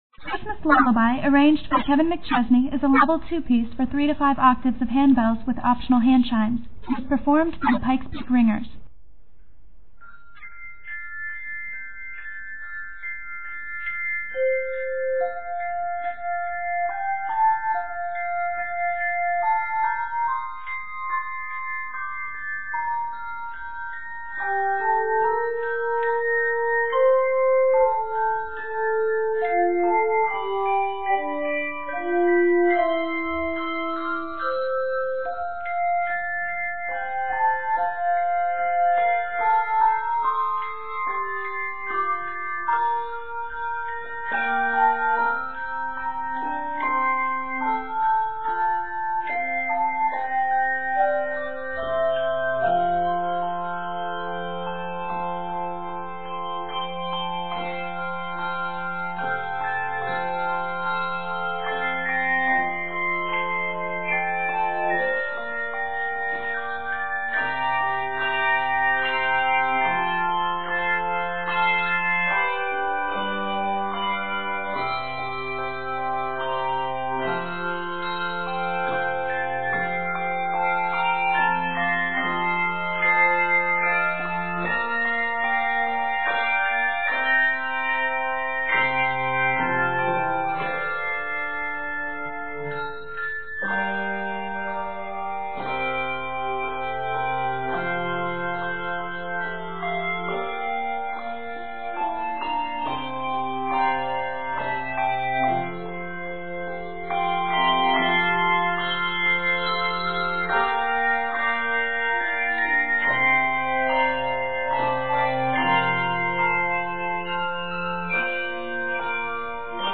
3 to 4-octave arrangement.